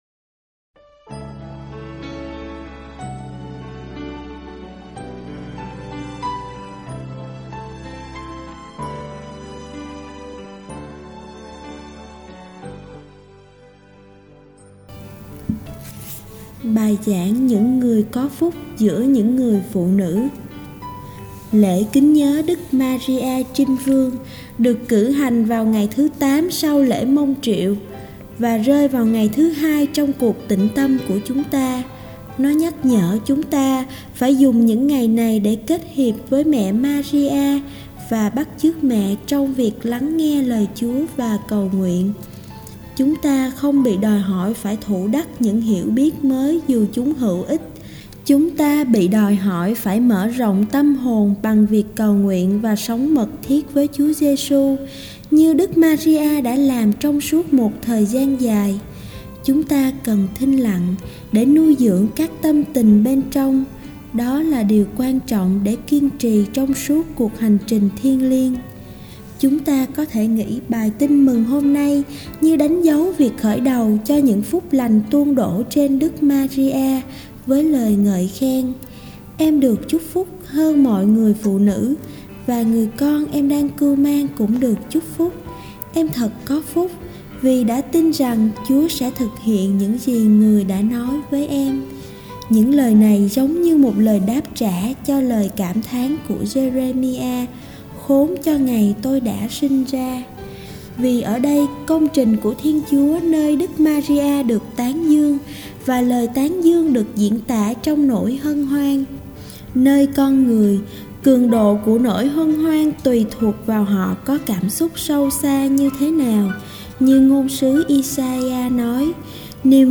06. Bai giang nguoi co phuc giua nhung nguoi phu nu.mp3